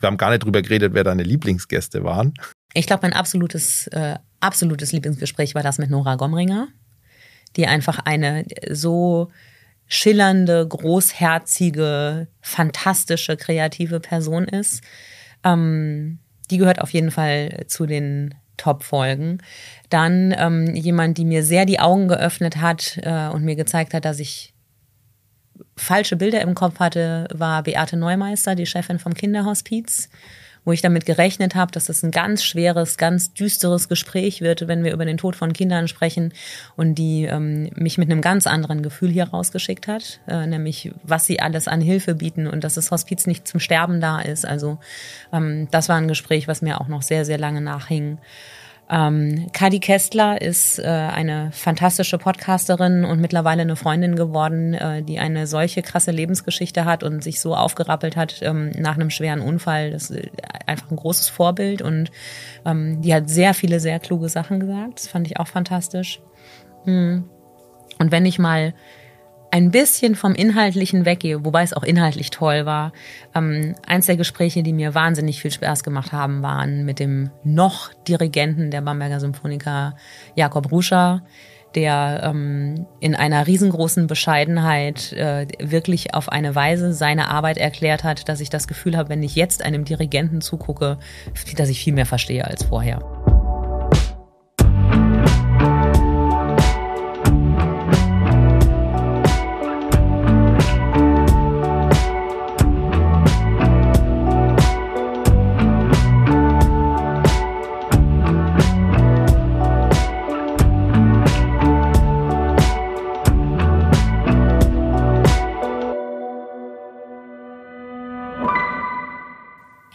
Der Interview-Podcast